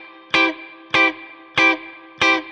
DD_StratChop_95-Cmaj.wav